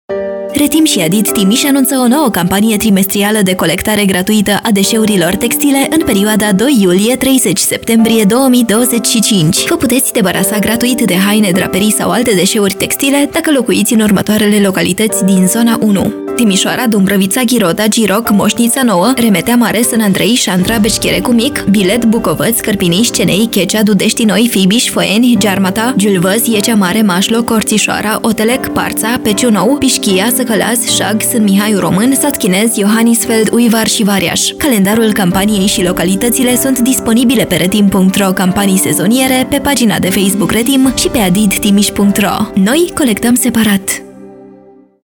Spot-Radio-Textile-Trim.-III-2025-Zona-1.mp3